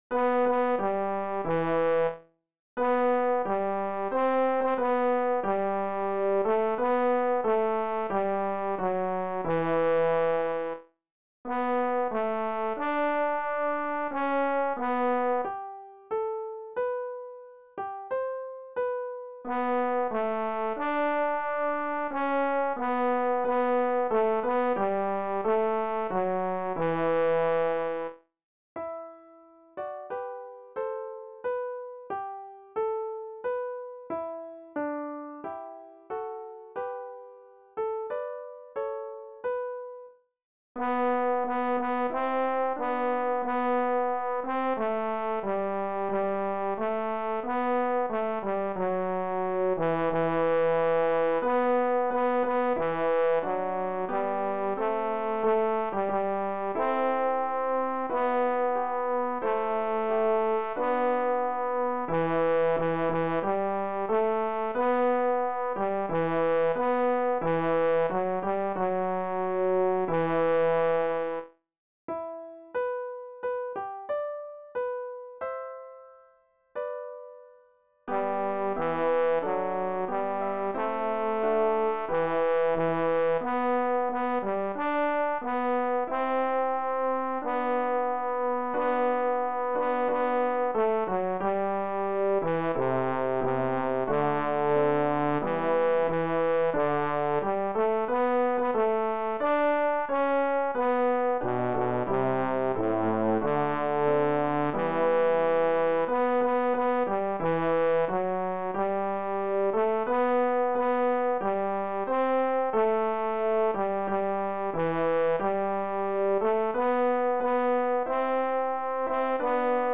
bárdos-missa-tertia-1944-gloria-bass.mp3